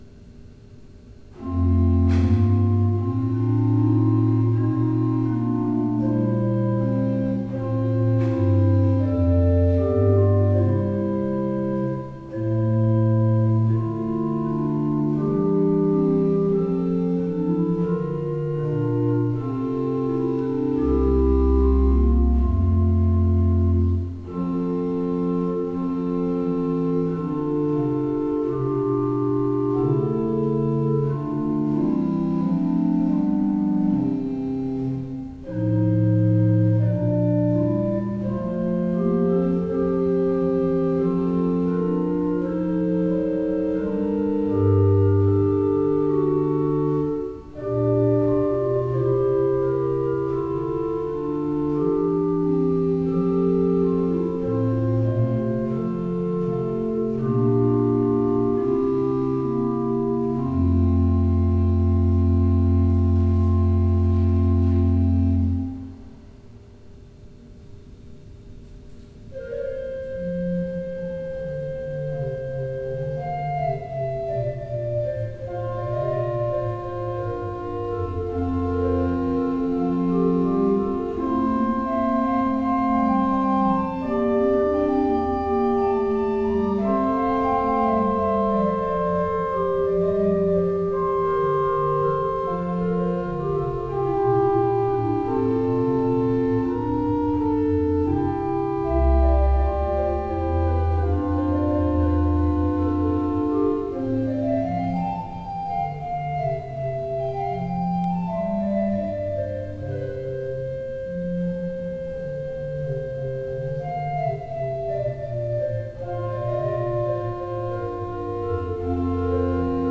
Postludes played at St George's East Ivanhoe 2024
The performances are as recorded on the Thursday evening prior the service in question and are recorded direct to PC using a Yeti Nano USB microphone..